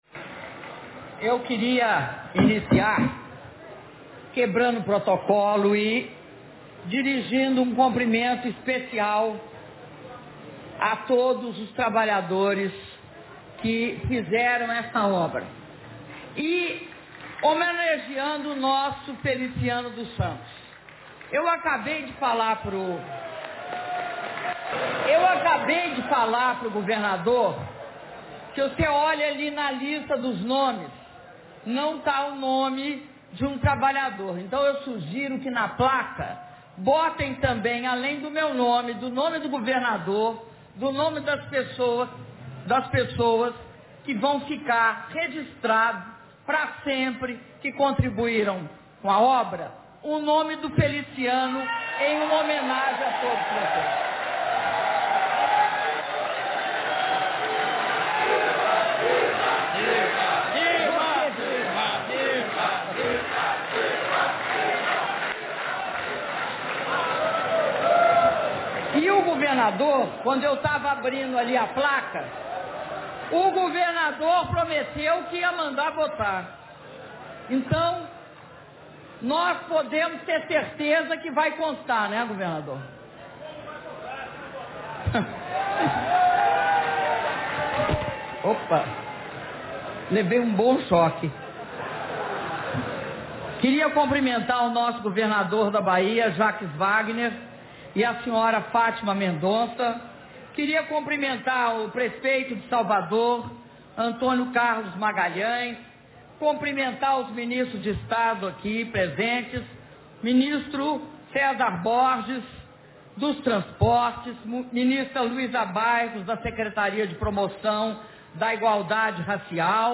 Áudio do discurso da Presidenta da República, Dilma Rousseff, na cerimônia de inauguração da Via Expressa Baía de Todos os Santos - Salvador/BA